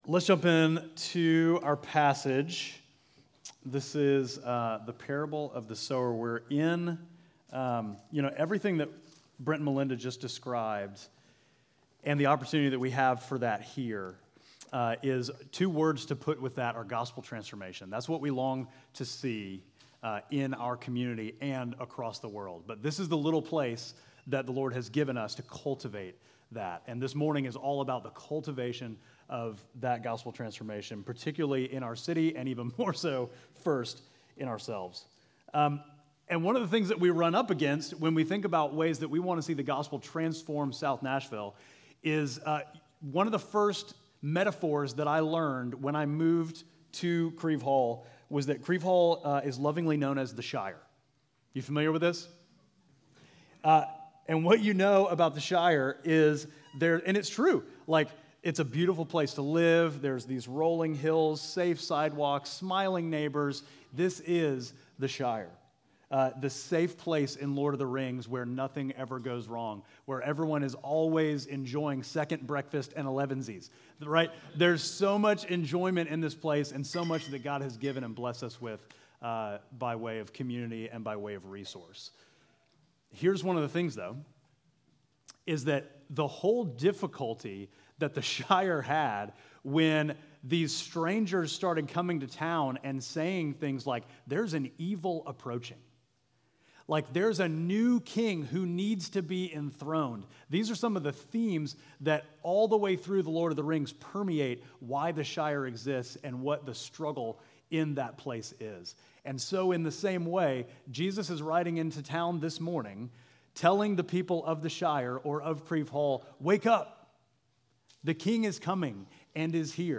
Midtown Fellowship Crieve Hall Sermons Parable of the Sower Apr 28 2024 | 00:25:38 1x 00:00 / 00:25:38 Subscribe Share Apple Podcasts Spotify Overcast RSS Feed Share Link Embed